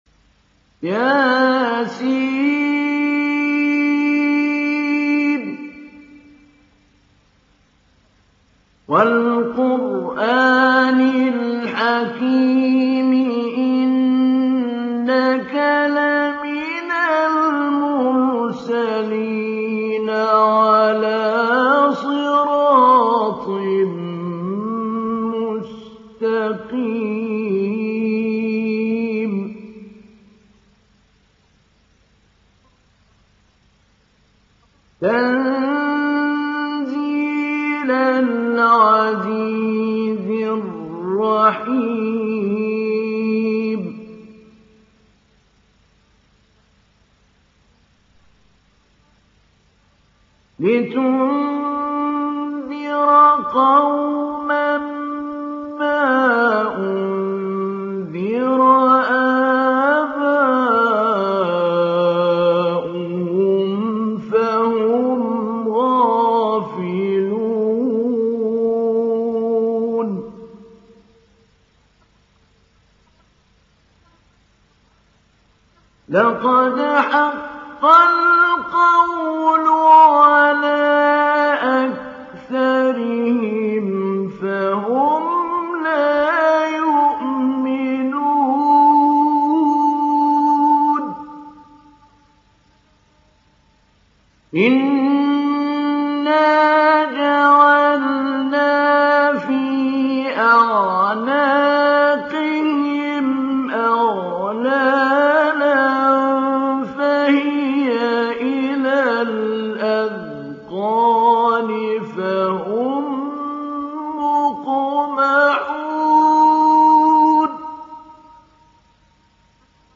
Download Surat Yasin Mahmoud Ali Albanna Mujawwad